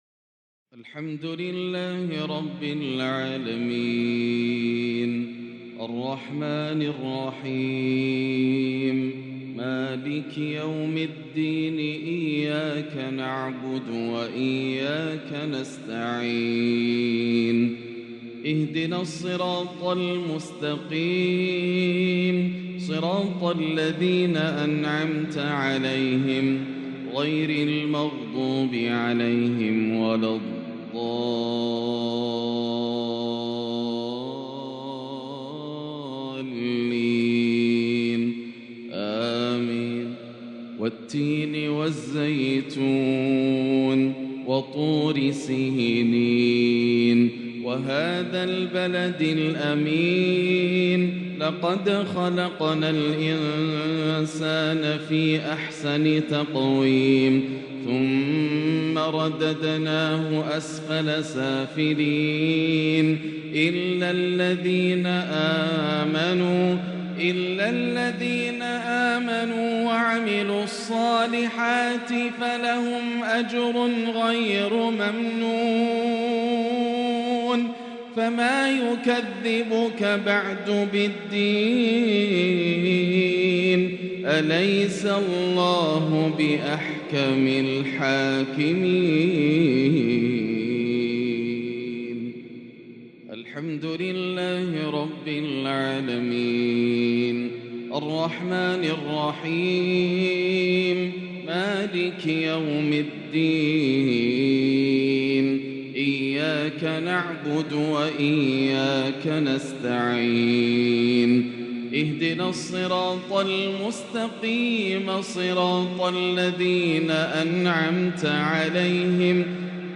بالعجم الماتع يتغنّى د. ياسر الدوسري بتلاوة هادئة تريح القلوب | صلاة المغرب ٨-٤-١٤٤٤هـ > تلاوات عام 1444هـ > مزامير الفرقان > المزيد - تلاوات الحرمين